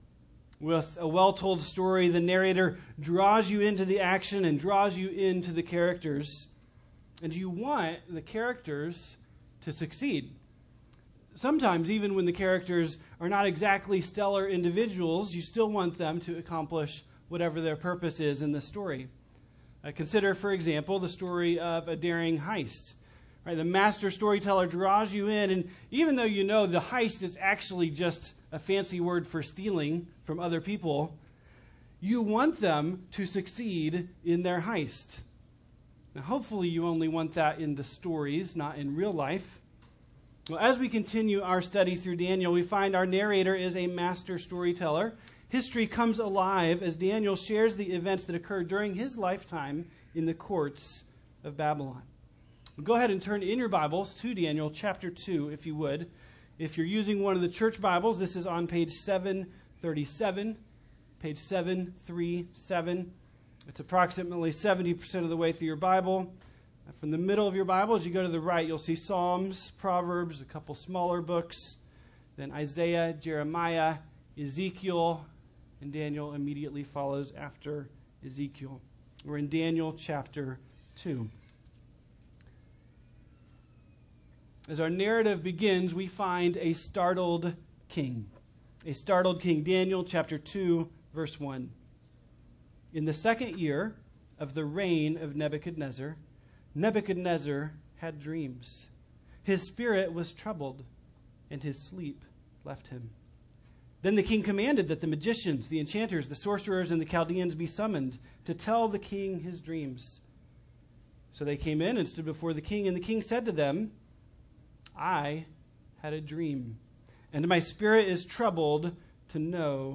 2017 ( Sunday AM ) Bible Text